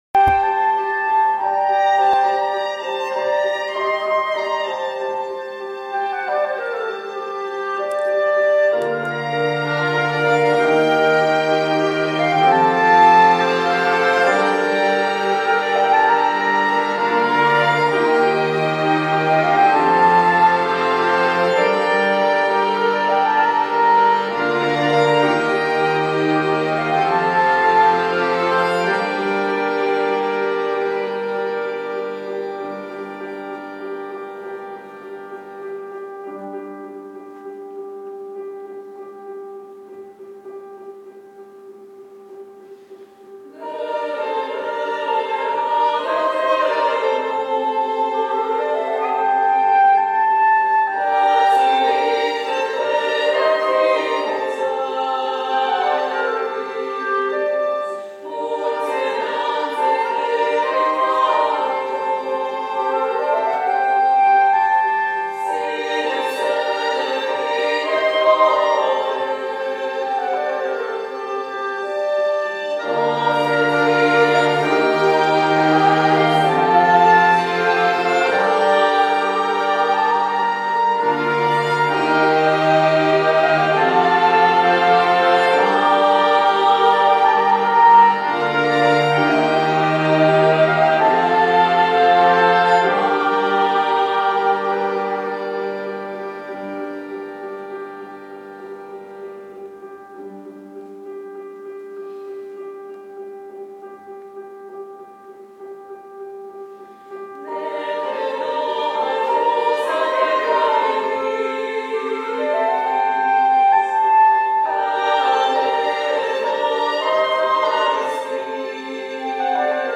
polska muzyka współczesna
chór żeński